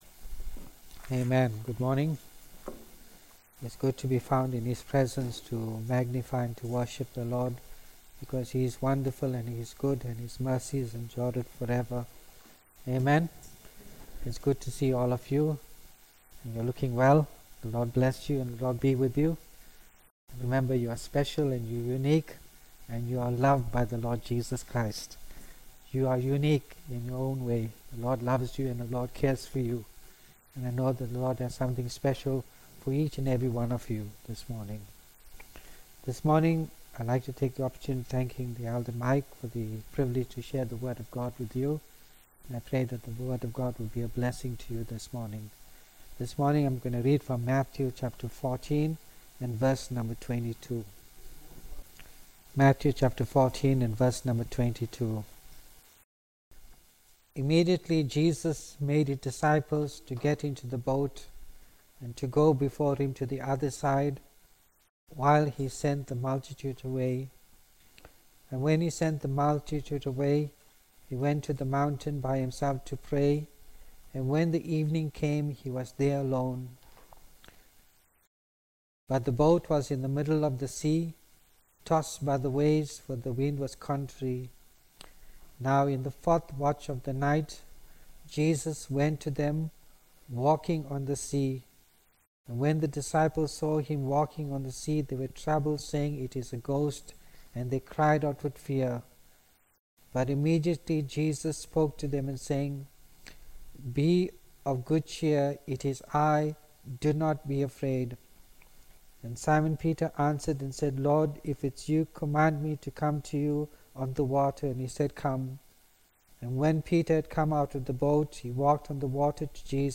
Play MP3 Audio SERMONS The Disciples in the Middle of the Sea https